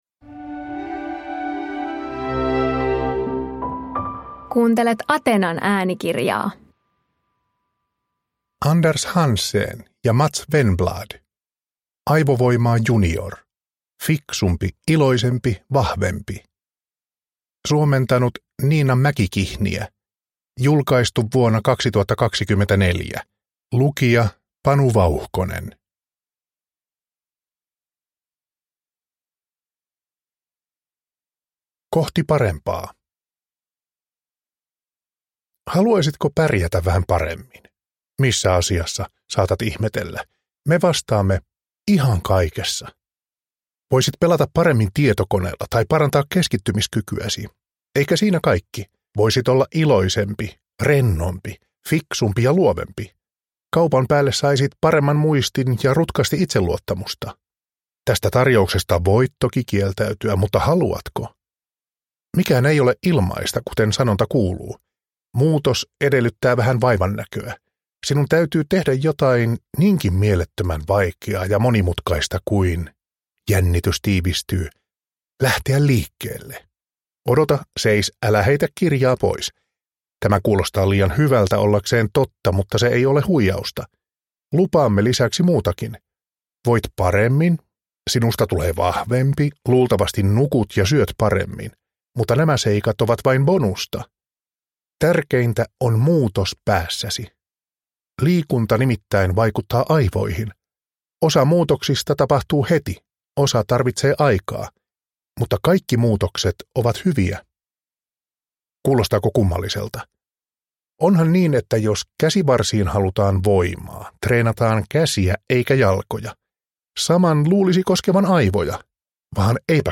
Aivovoimaa junior – Ljudbok